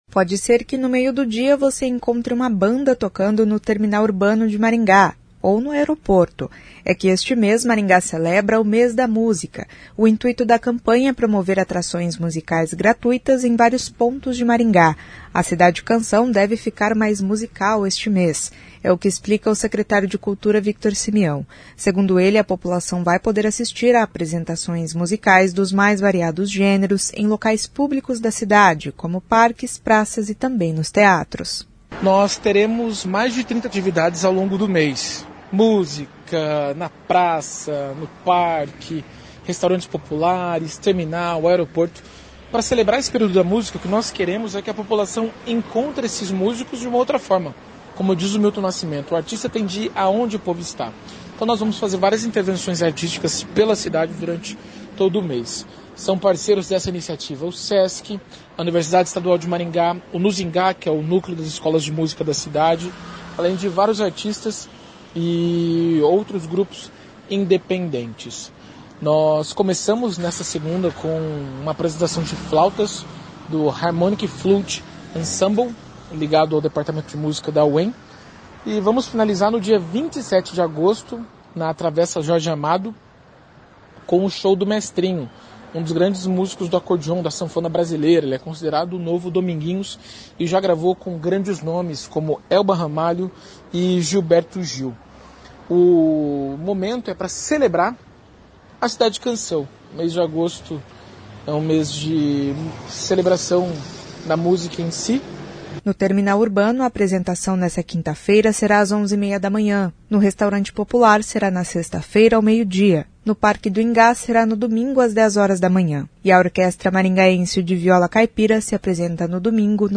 É o que explica o secretário de Cultura, Victor Simião.